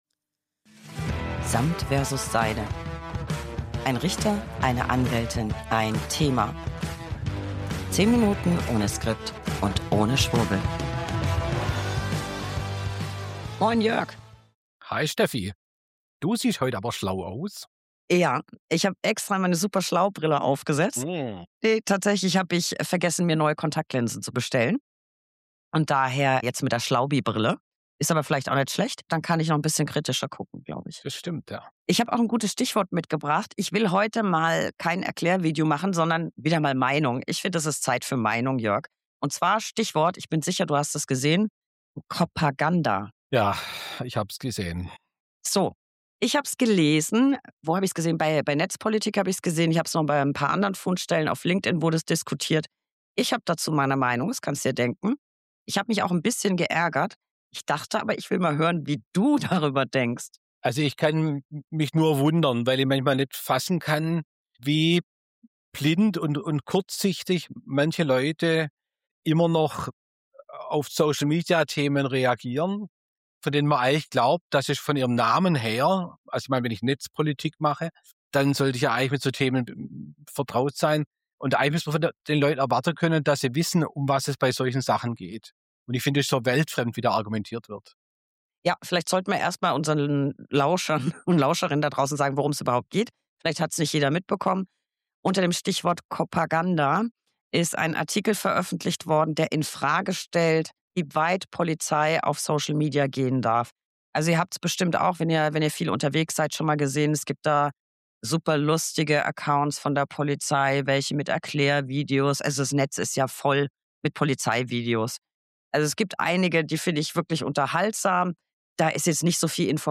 1 Anwältin + 1 Richter + 1 Thema. 10 Minuten ohne Skript und ohne Schwurbel.